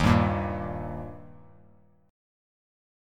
Eb Chord
Listen to Eb strummed